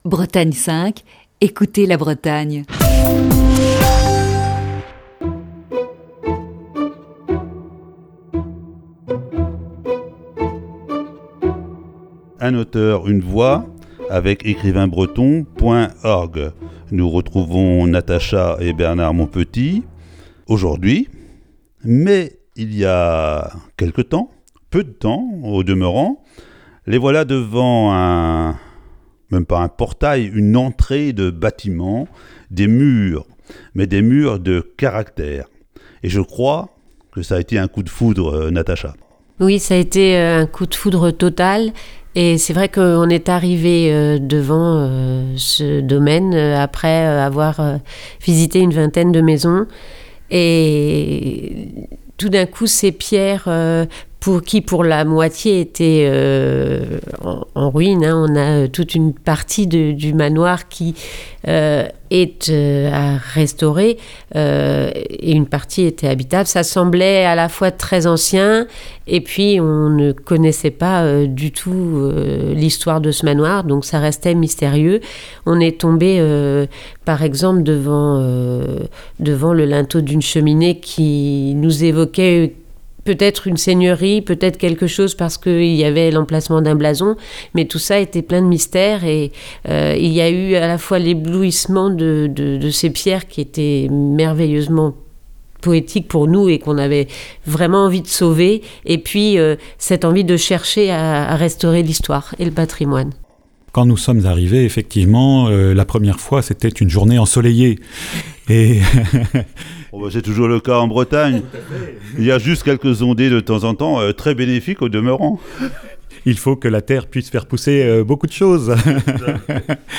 Voici ce matin la troisième partie de cette série d'entretiens.